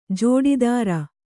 ♪ jōḍidāra